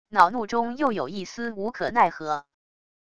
恼怒中又有一丝无可奈何wav音频